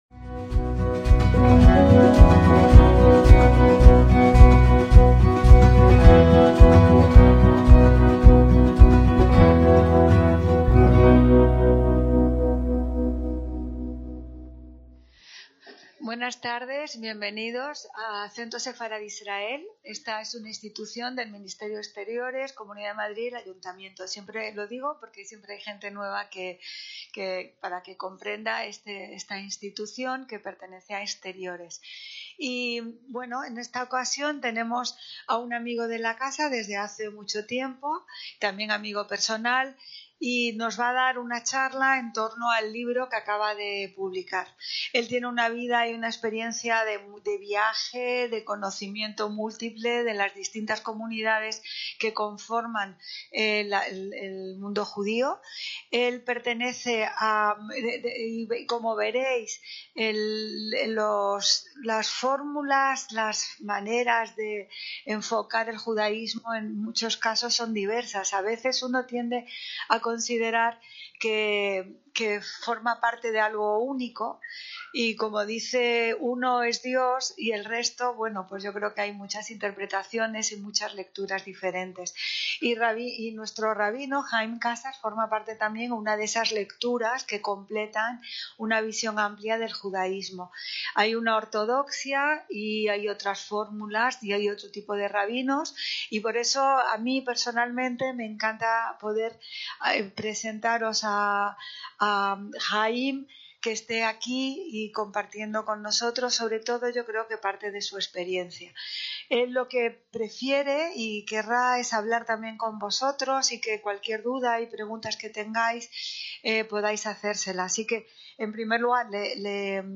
Introducción al judaísmo: un viaje desde los tiempos bíblicos hasta la actualidad (Centro Sefarad Israel, Madrid, 14/1/2025)